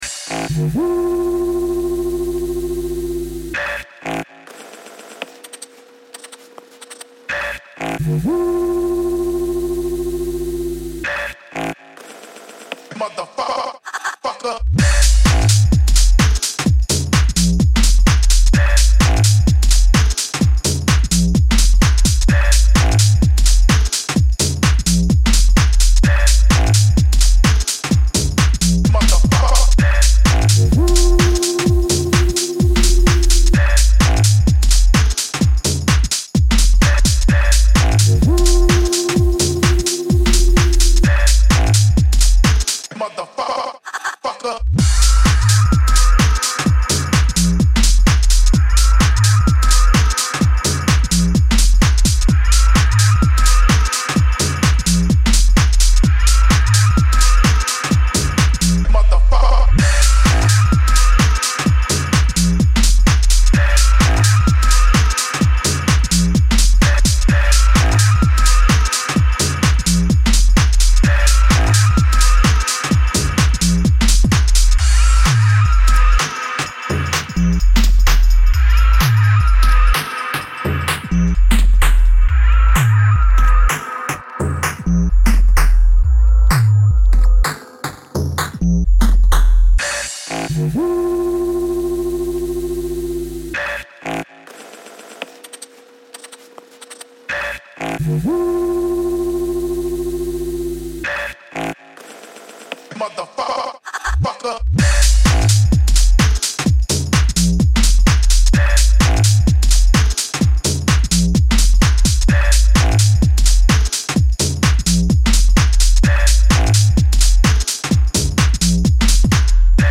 There's wub-wub filth underpinning the garage shuffler